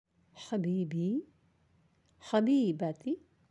(Habibi / Habibti)
habibi-habibati.aac